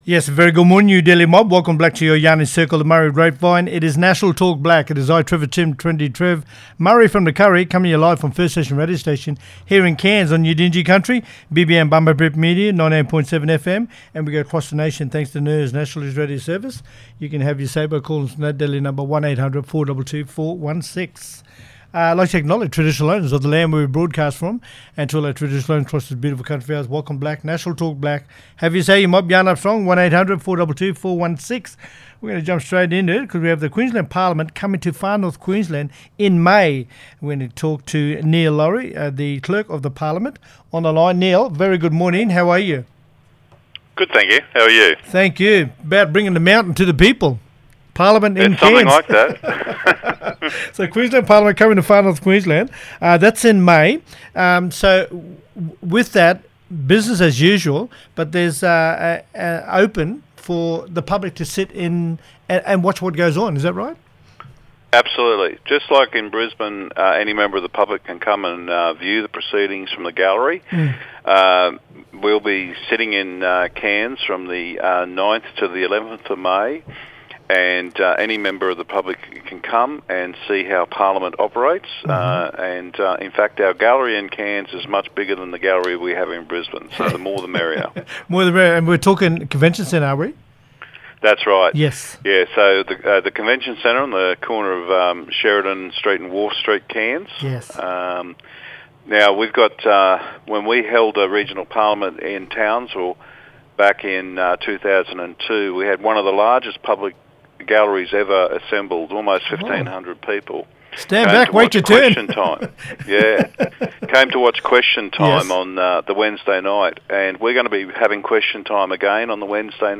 Neil Laurie, The Clerk of the Parliament, talking about how the Queensland Parliament is coming to Far North Queensland in May.